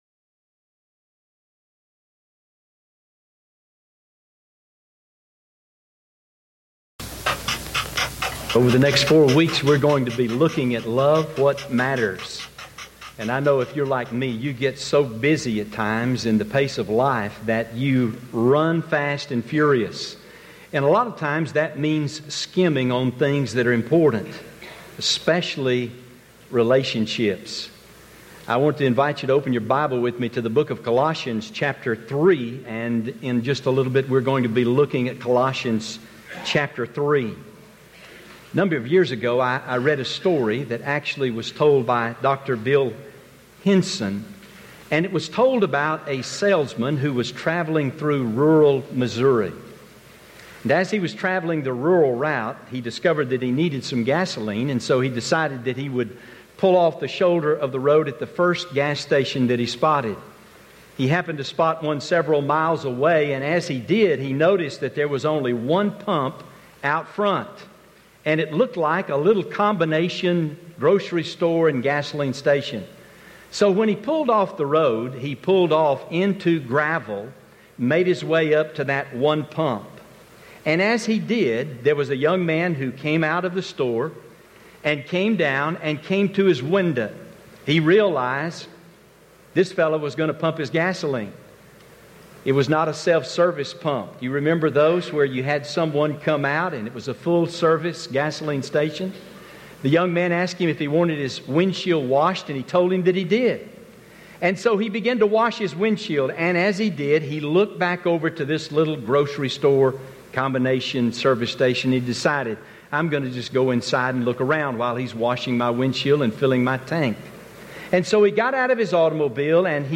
First Baptist Church of Glen Rose Audio Sermons
AM Service